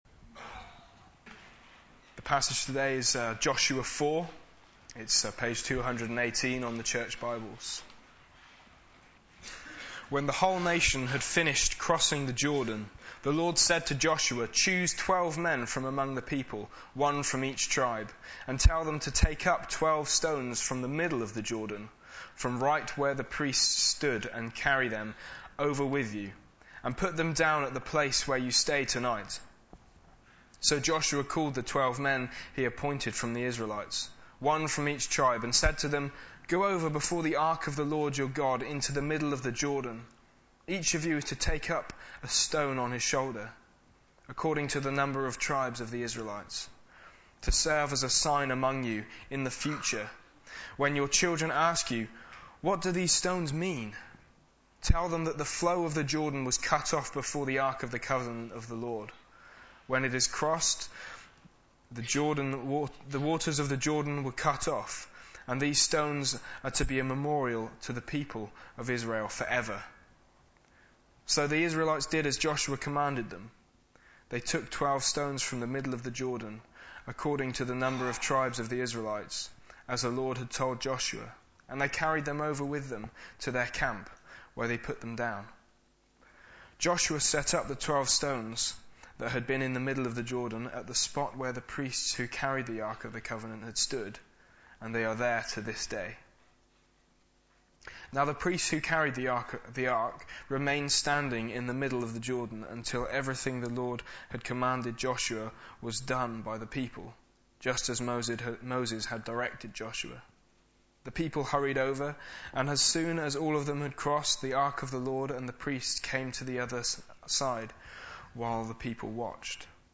Sunday Service
The Witness of Faith Sermon